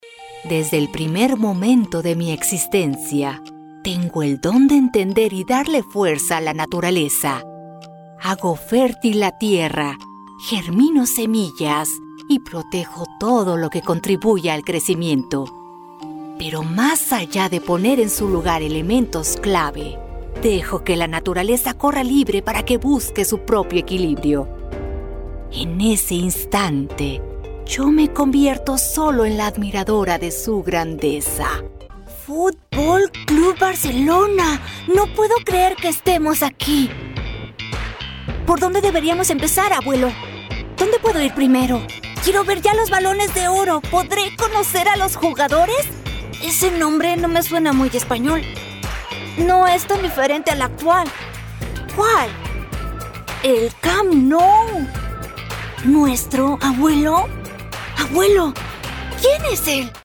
Kommerziell, Natürlich, Cool, Vielseitig, Corporate
Persönlichkeiten